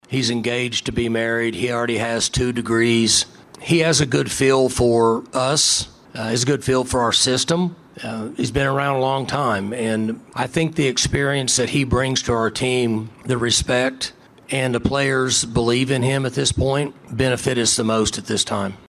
Head coach Mike Gundy talks about Bowman’s maturity.